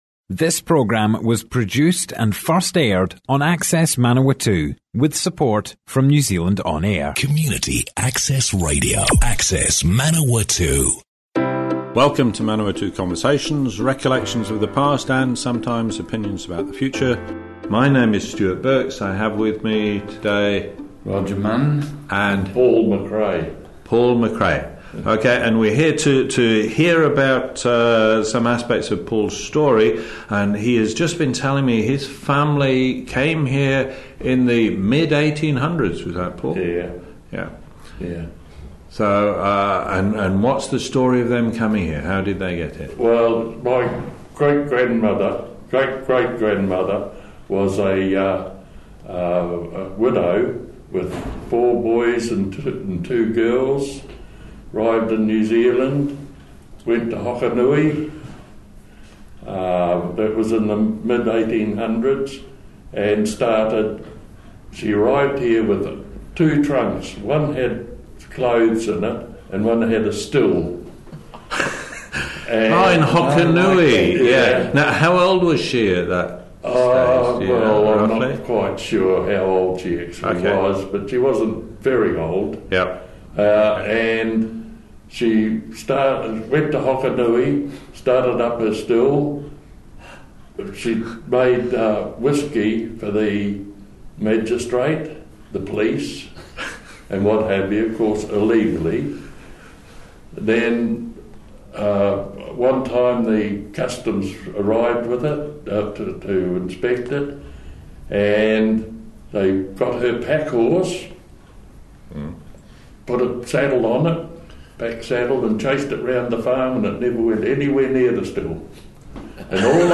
(Hammering noise in background). Schooling in Palmerston North.